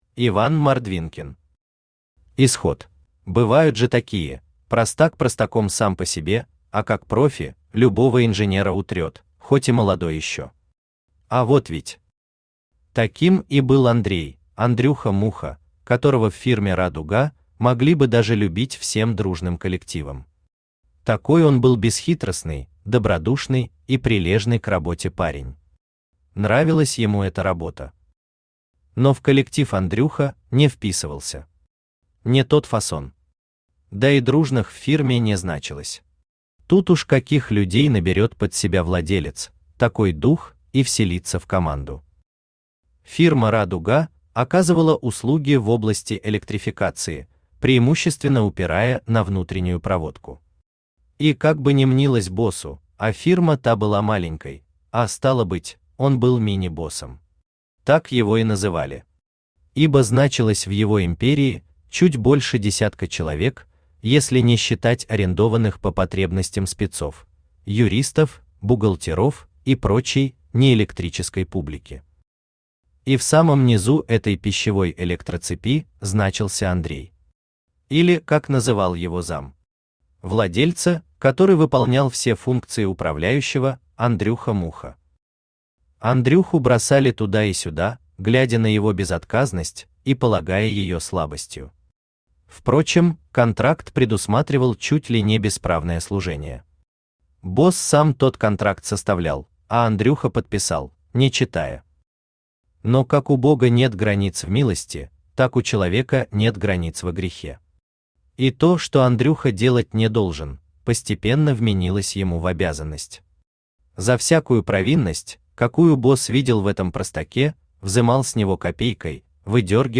Аудиокнига Исход | Библиотека аудиокниг
Aудиокнига Исход Автор Иван Александрович Мордвинкин Читает аудиокнигу Авточтец ЛитРес.